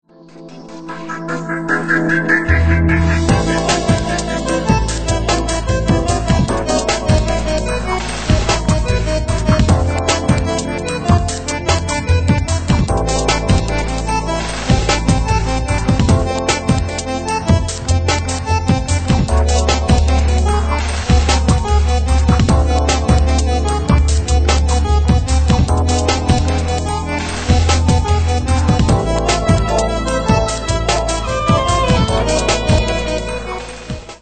Moderne Tangos/ Tango-Atmosphäre